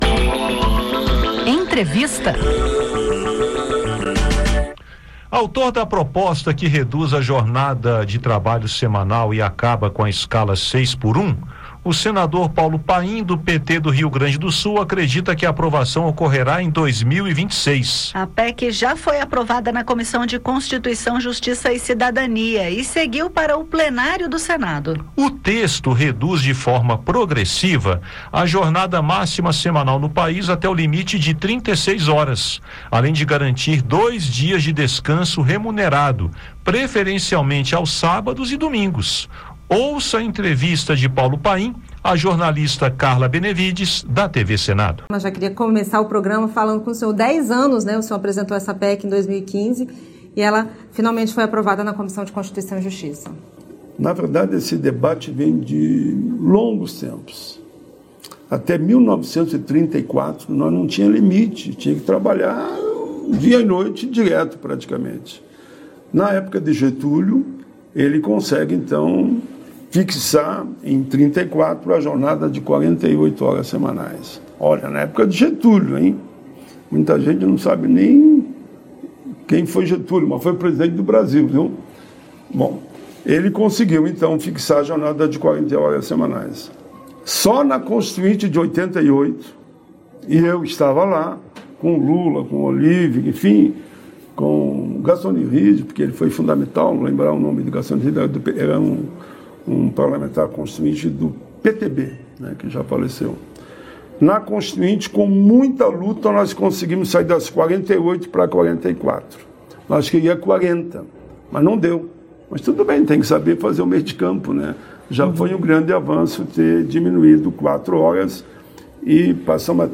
A proposta prevê a redução gradual da jornada máxima para 36 horas semanais, sem corte de salários, além da garantia de dois dias de descanso remunerado. O senador fala sobre o tema em entrevista